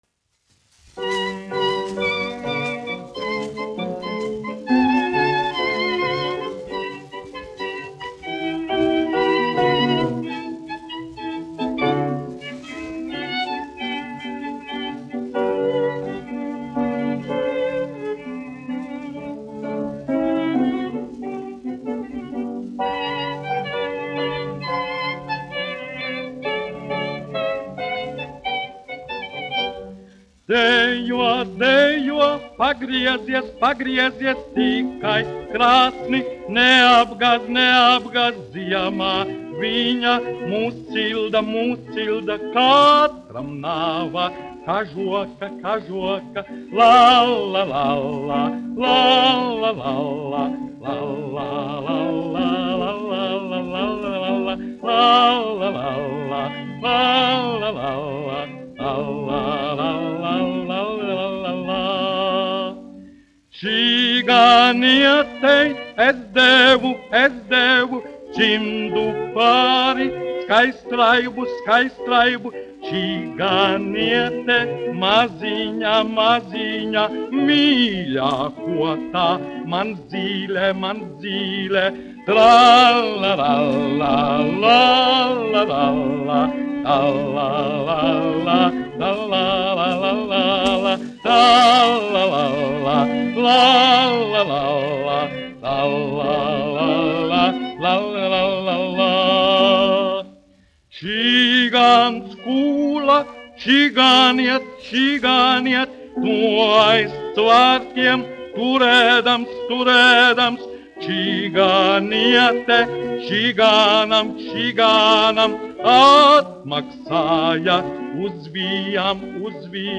1 skpl. : analogs, 78 apgr/min, mono ; 25 cm
Slovāku tautasdziesmas
Latvijas vēsturiskie šellaka skaņuplašu ieraksti (Kolekcija)